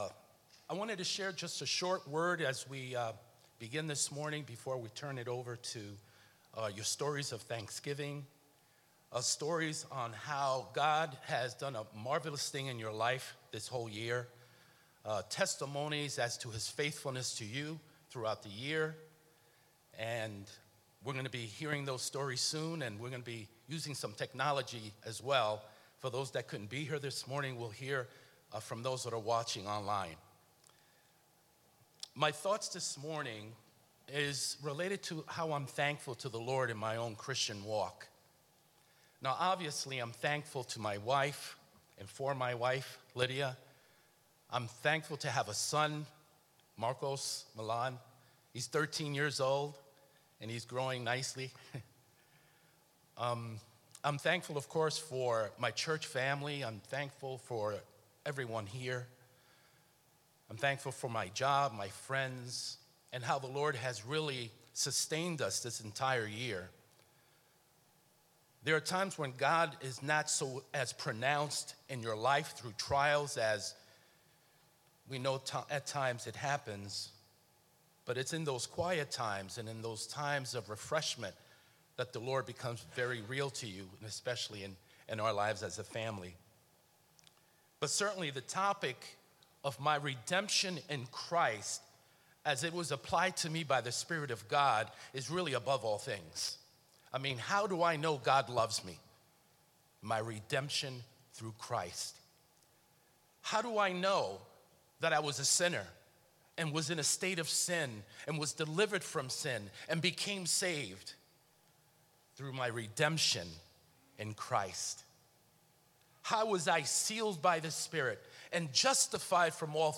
Thanksgiving Service 2020